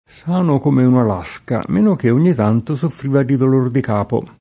meno che [m%no k%+ o m%no ke+] (meno com. menoché [menok%+]) cong. — lo stesso che «tranne che»: sano come una lasca; menoché ogni tanto soffriva di dolor di capo [
S#no k1me una l#Ska; menok% on’n’i t#nto Soffr&va di dol1r di k#po] (Cicognani)